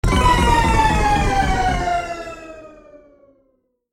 دانلود آهنگ کشتی 12 از افکت صوتی حمل و نقل
جلوه های صوتی
دانلود صدای کشتی 12 از ساعد نیوز با لینک مستقیم و کیفیت بالا